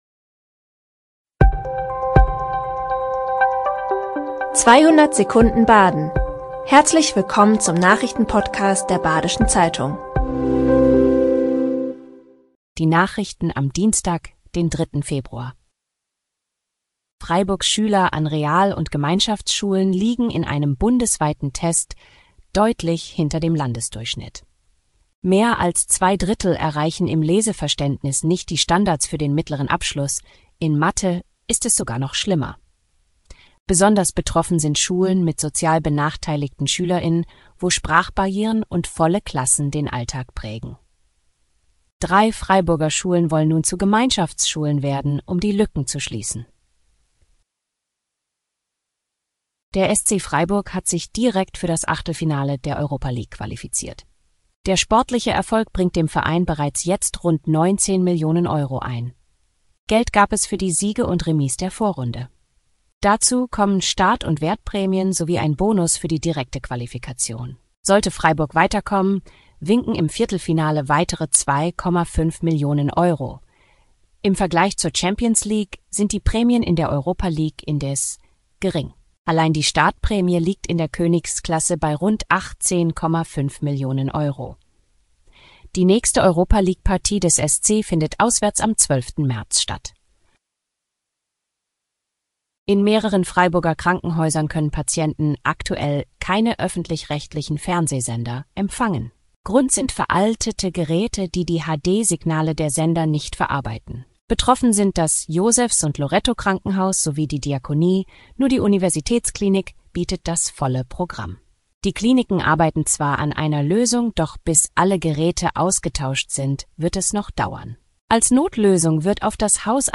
5 Nachrichten in 200 Sekunden.